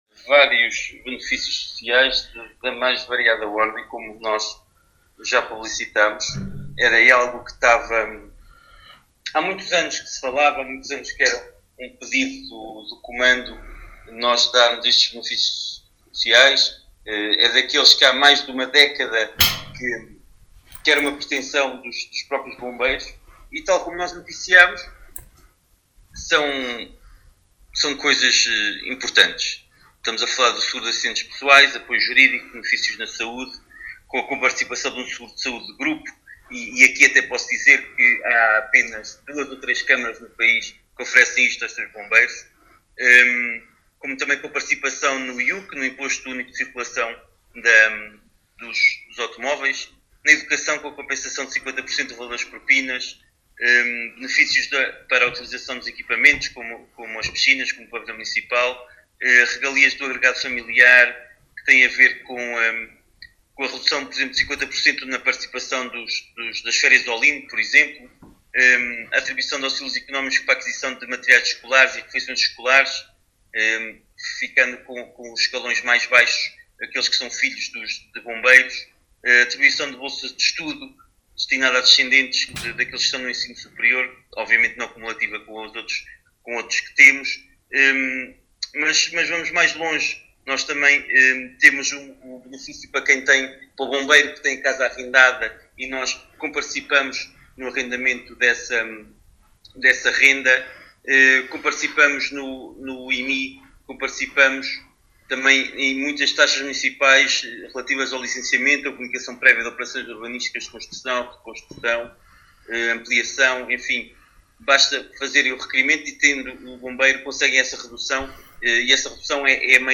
Paulo Marques, Presidente do Município de Vila Nova de Paiva, em declarações à Alive FM, fala destes benefícios.